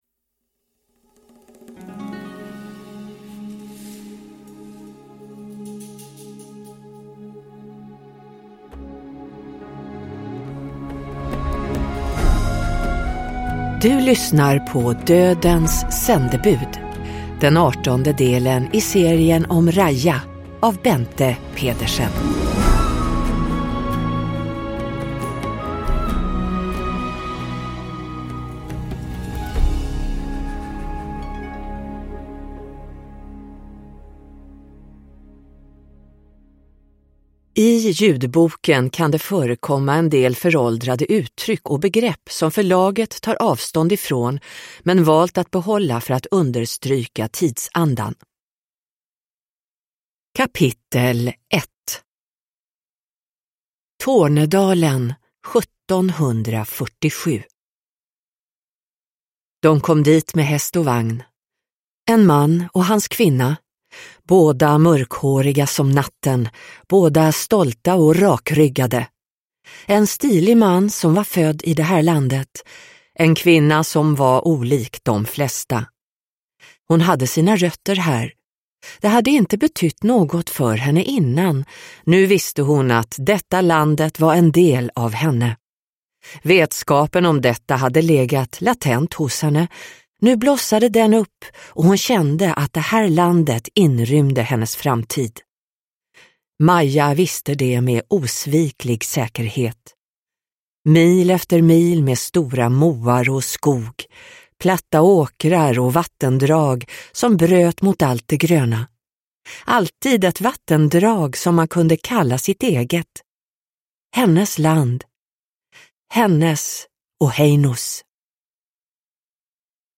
Dödens sändebud – Ljudbok – Laddas ner